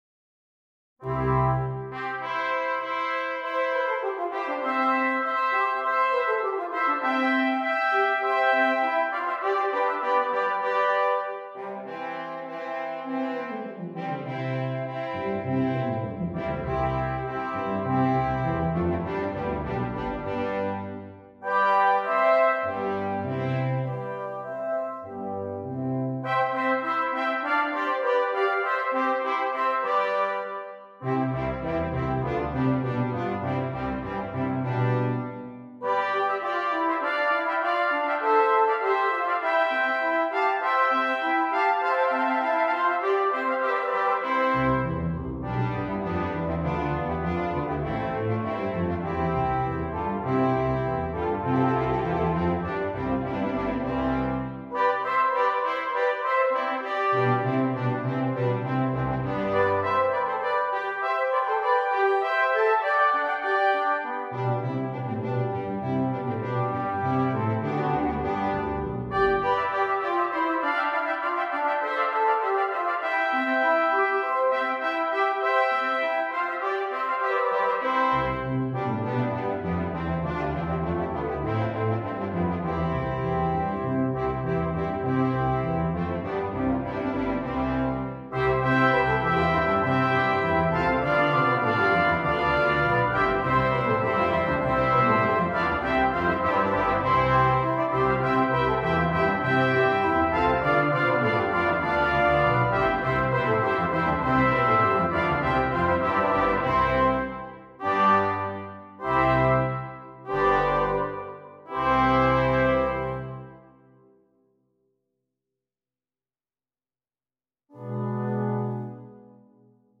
Double Brass Quintet